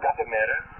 I had decided to focus on EVP during this room session so I did not set up the mirrors. This EVP seems to indicate that they were expecting a photo session as is usually the case.